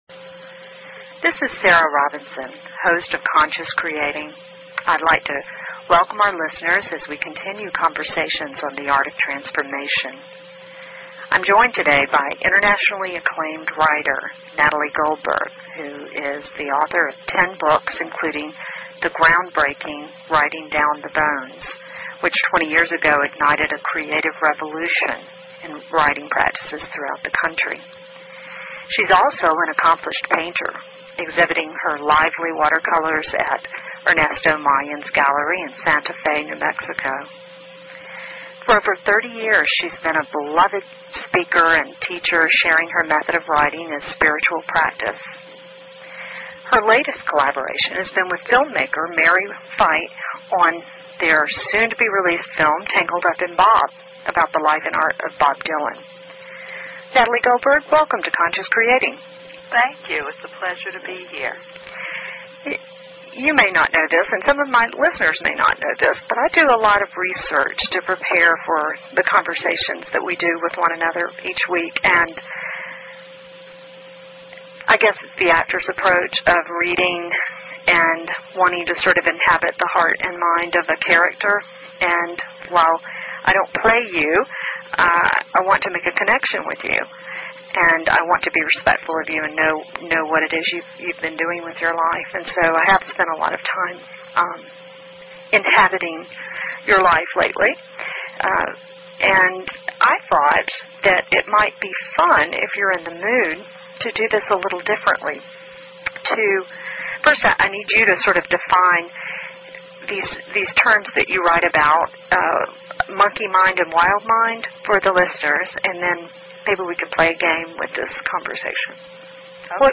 Talk Show Episode, Audio Podcast, Conscious Creating and Courtesy of BBS Radio on , show guests , about , categorized as